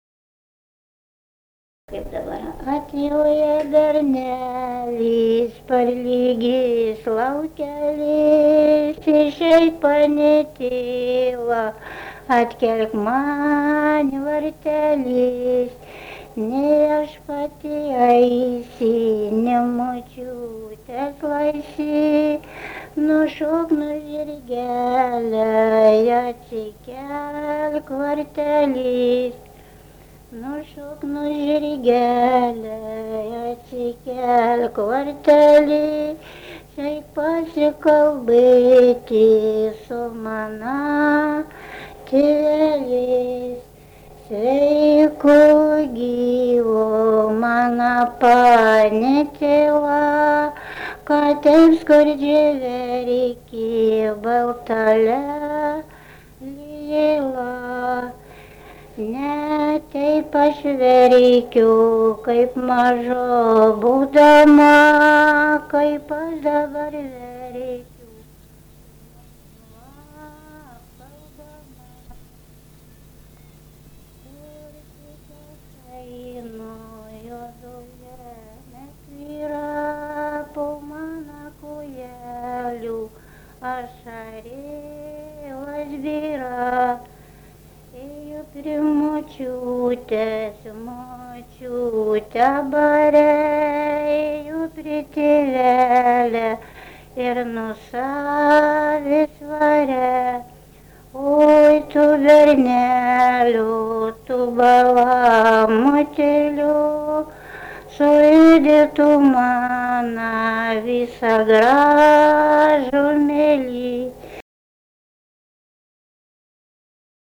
daina, vestuvių
Sereikoniai
vokalinis